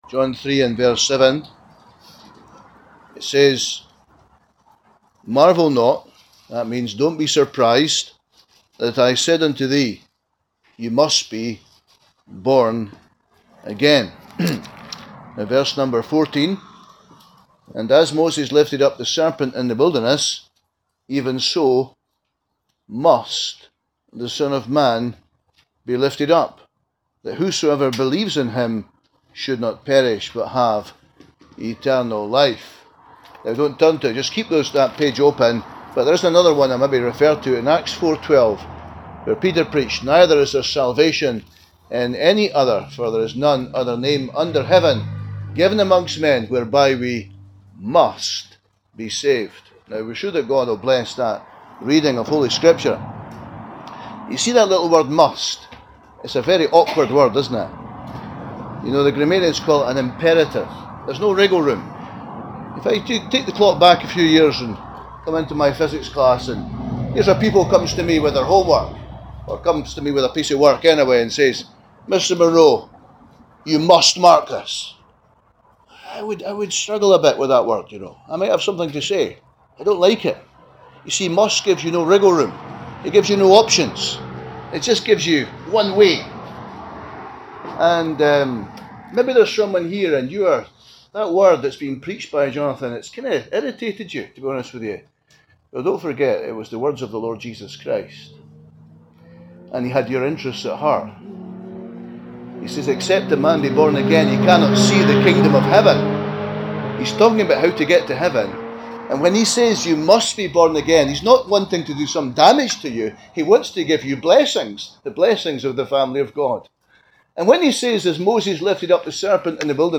2025 Gospel Tent